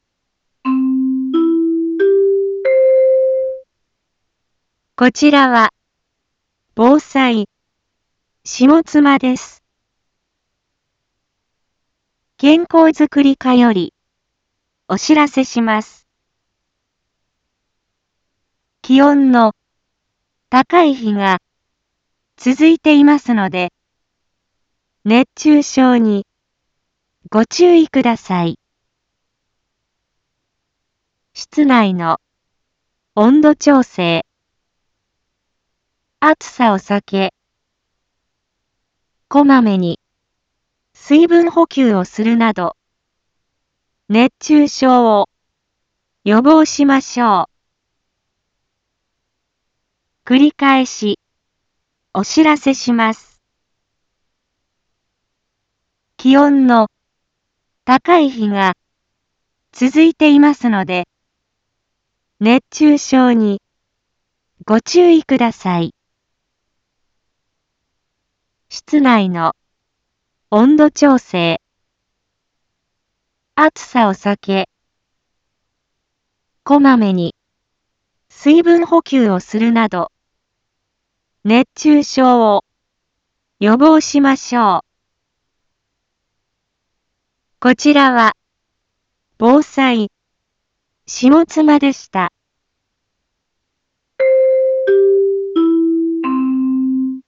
一般放送情報
Back Home 一般放送情報 音声放送 再生 一般放送情報 登録日時：2024-08-13 11:01:42 タイトル：熱中症注意のお知らせ インフォメーション：こちらは、ぼうさい、しもつまです。